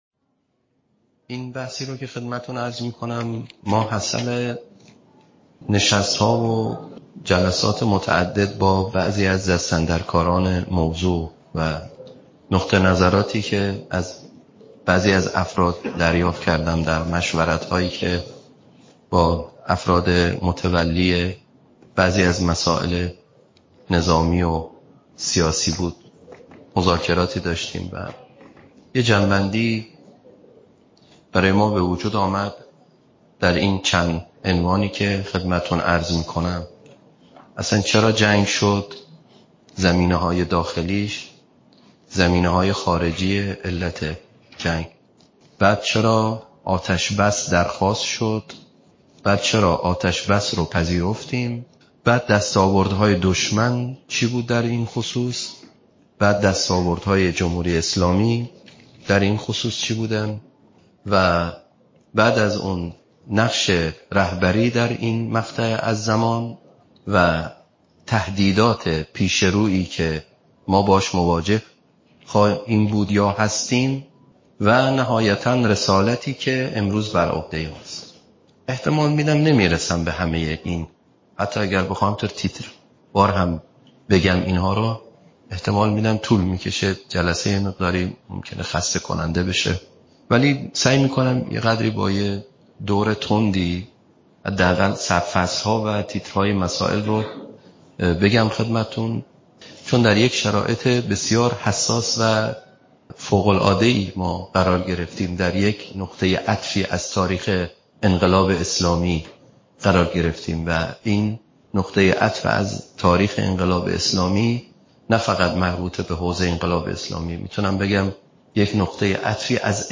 نشست بصیرتی جهاد تبیین تبیین مسائل ایران و منطقه غرب آسیا ۲۰ تیر۱۴۰۴ اهواز _ مسجد امام موسی‌بن‌جعفر